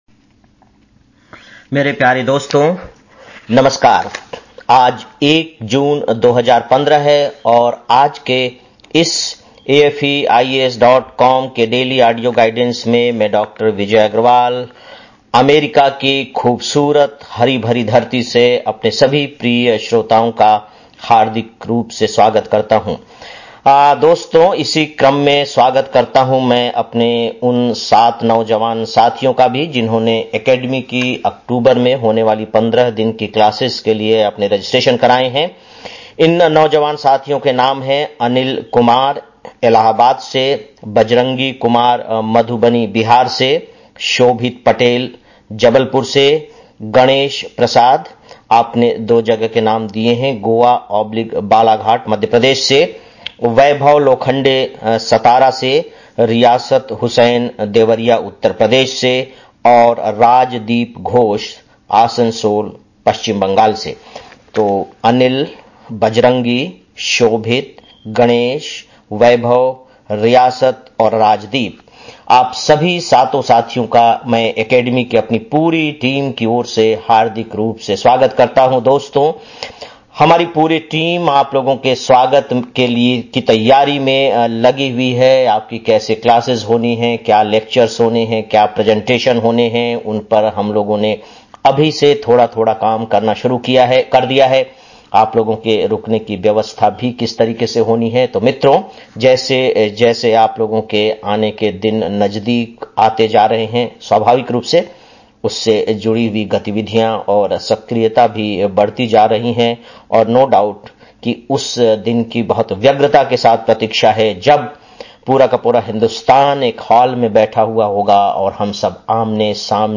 01-06-15 (Daily Audio Lecture) - AFEIAS